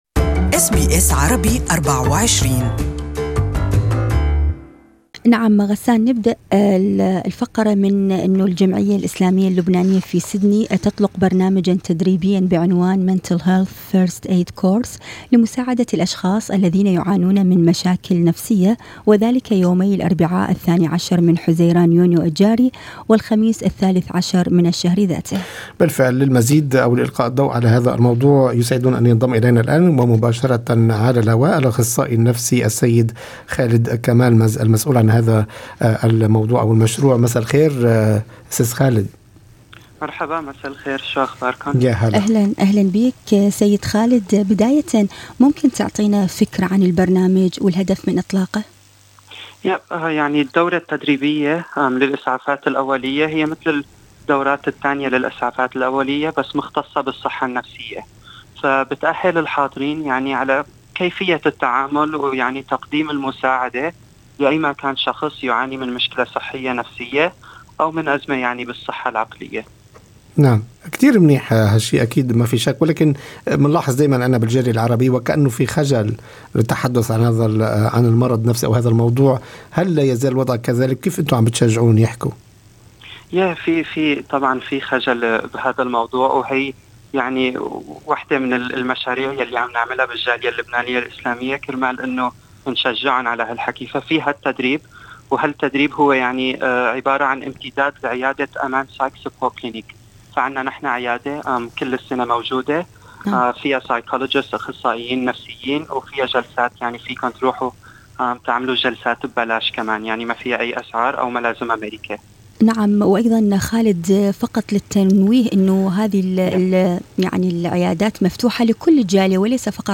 لقاءِ مباشر